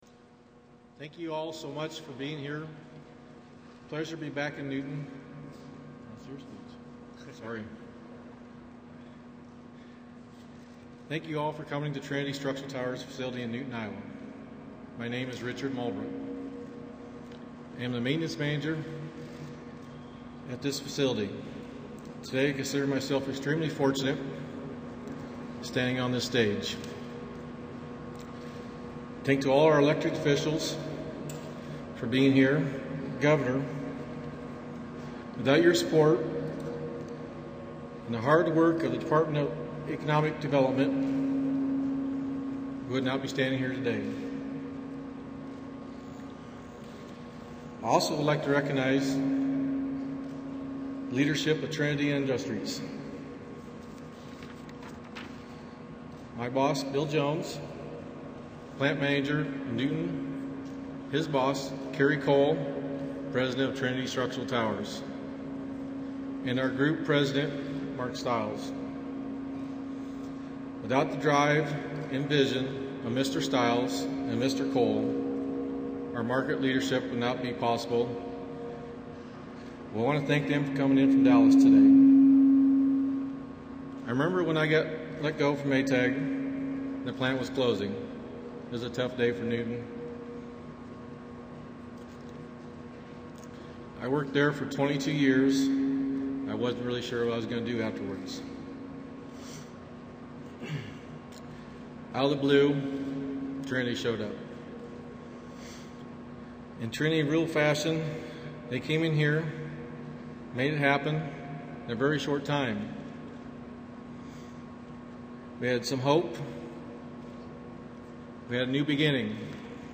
U.S. President Barack Obama speaks at green manufacturer and former Maytag plant, Trinity Structural Towers, in Newton, Iowa